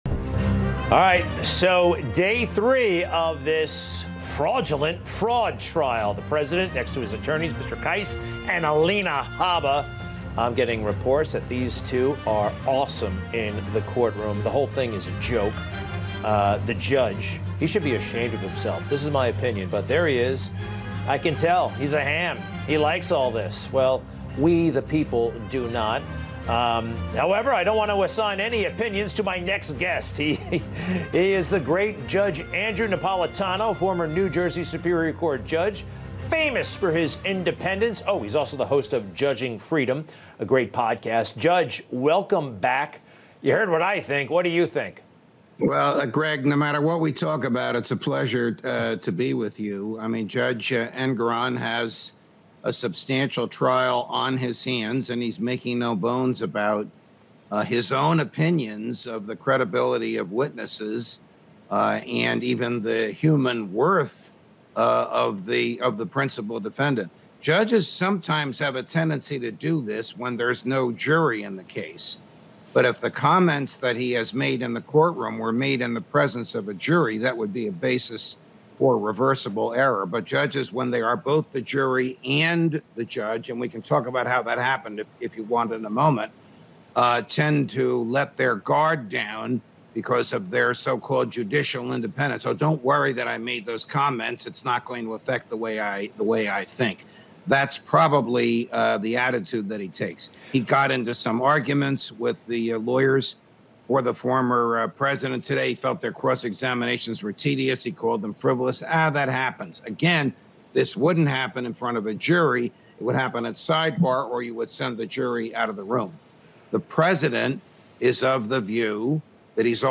➡ The text discusses a controversial trial where the judge’s impartiality is questioned due to seemingly biased comments made against the former president and his lawyers. Meanwhile, the host introduces guest Judge Napolitano, who focuses on the legality of the situation and shares a personal anecdote about a discussion on the JFK assassination files with President Trump.